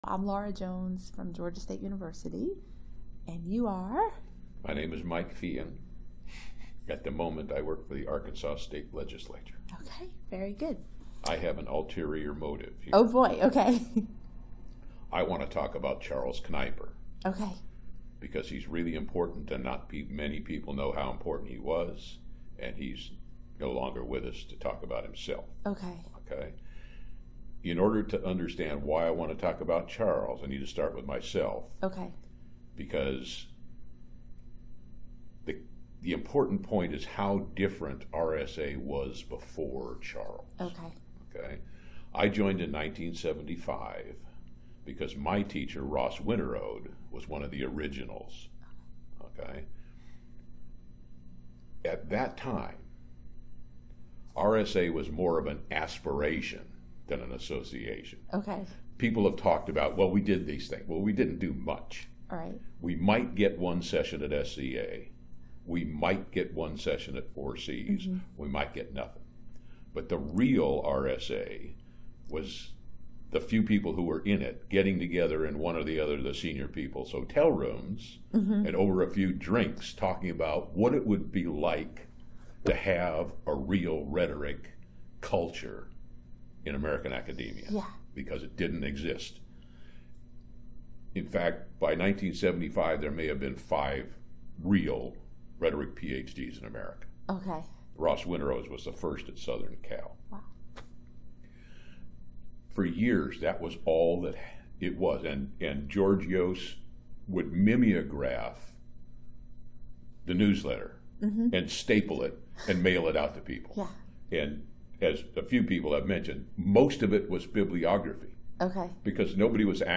Type Oral History
Location 2018 RSA Conference in Minneapolis, Minnesota